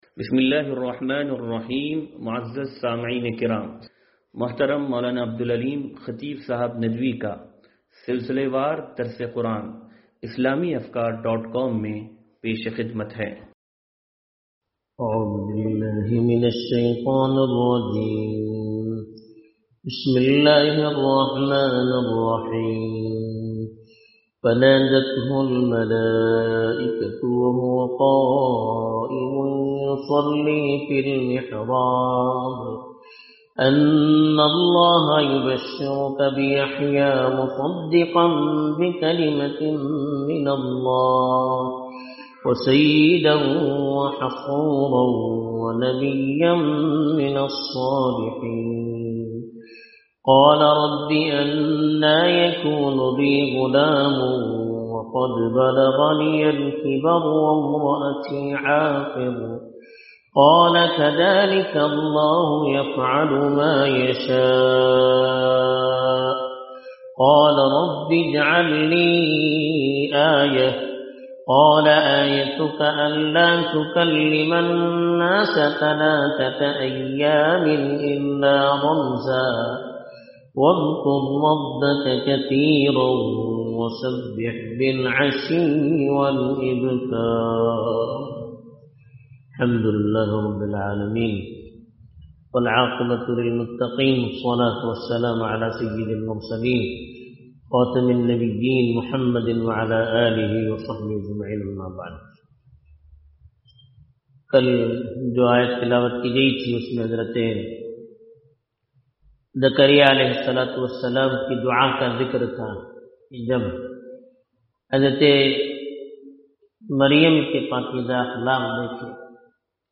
درس قرآن نمبر 0240